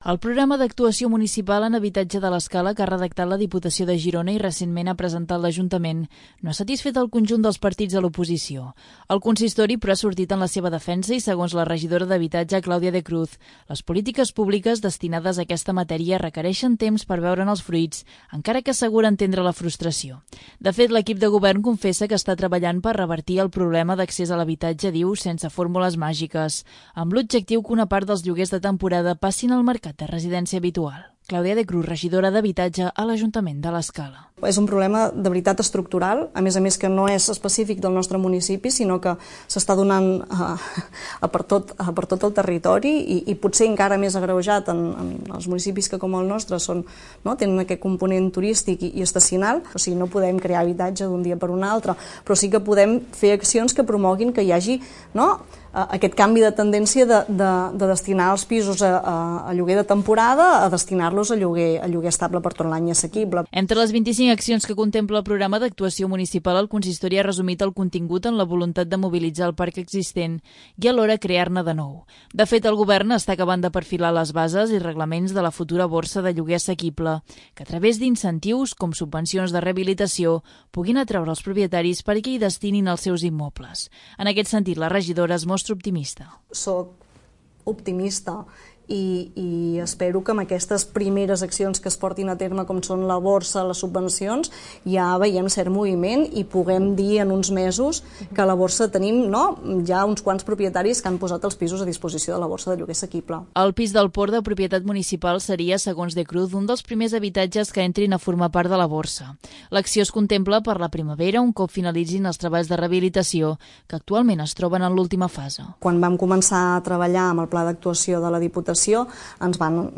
La regidora, Clàudia de Cruz, ha realitzat el conjunt de les declaracions en l'entrevista d'aquesta setmana 'La Casa de la Vila'.